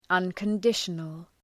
Shkrimi fonetik{,ʌnkən’dıʃənəl}
unconditional.mp3